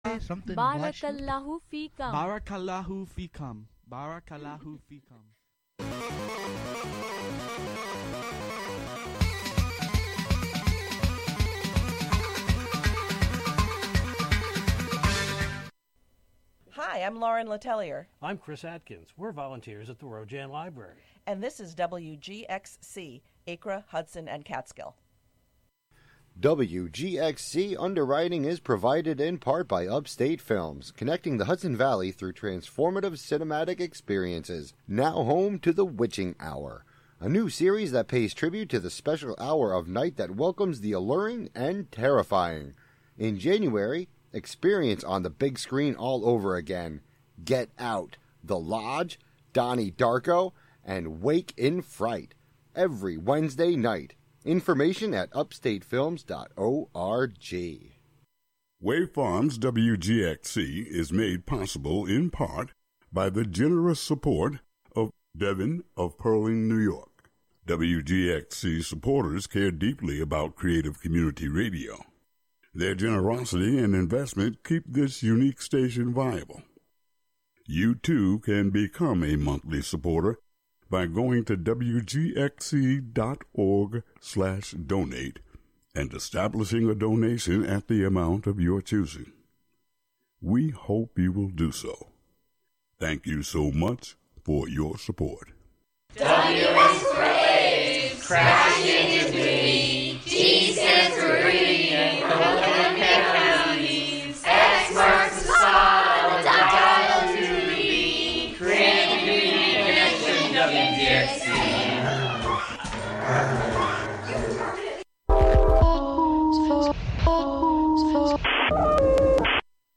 This music mix show